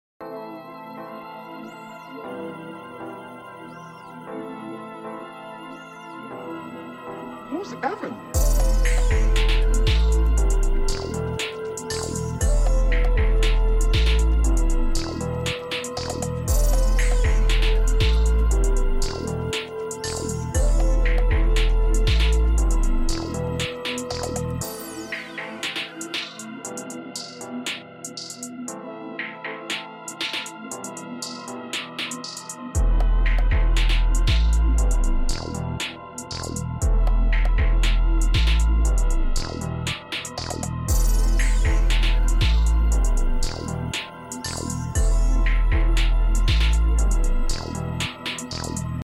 type beat